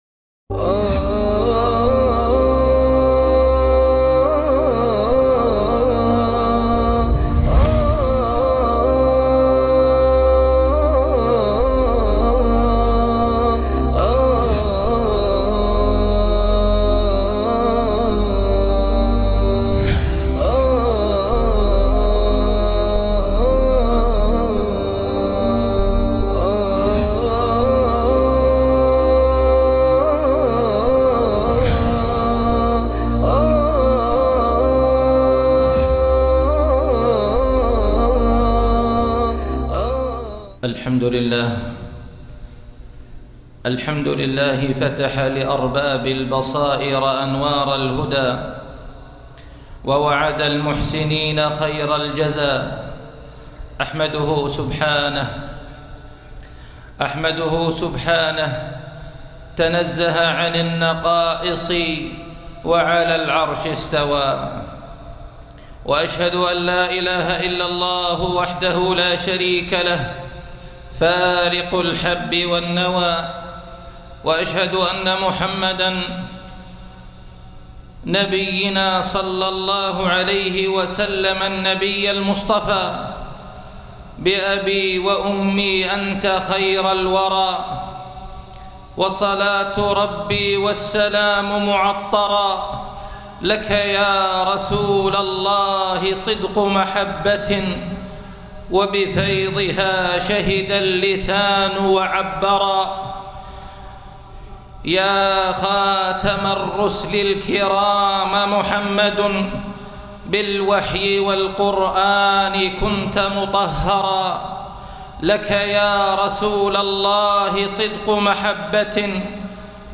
الأغصان خطبة جمعة